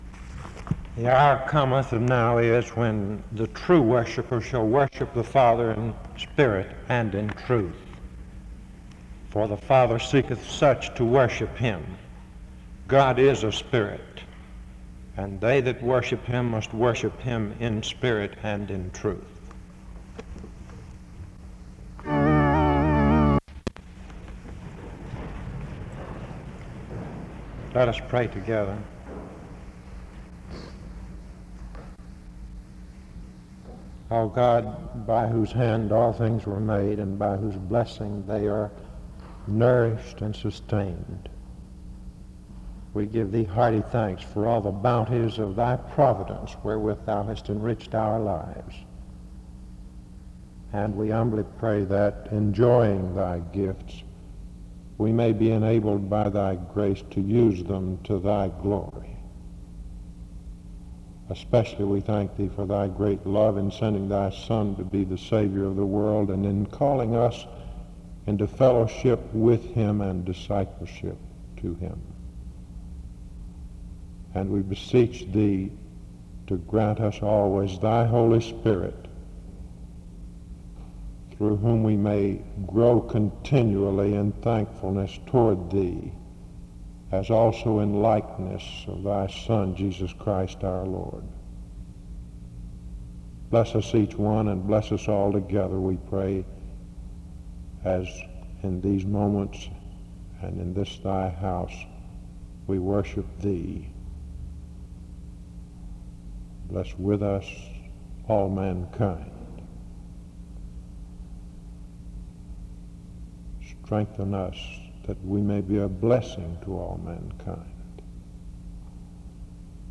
The service starts with an opening scripture reading and prayer from 0:00-2:52. The speaker is introduced from 2:53-4:11.